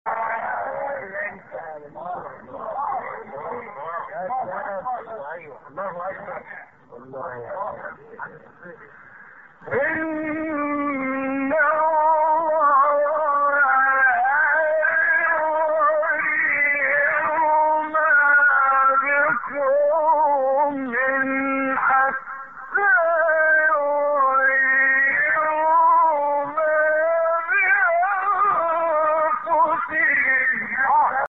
گروه فعالیت‌های قرآنی: فرازهایی در مقام صبا با صوت محمد عمران ارائه می‌شود.
برچسب ها: خبرگزاری قرآن ، ایکنا ، فعالیت های قرآنی ، مقام صبا ، محمد عمران ، قاری مصری ، فراز صوتی ، نغمه ، قرآن ، iqna